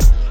Shady_Kick_2.wav